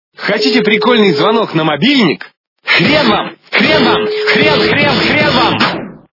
» Звуки » Смешные » Хотите прикольный звонок на мобильник? - Хрен Вам! Хрен Вам! Хрен Вам!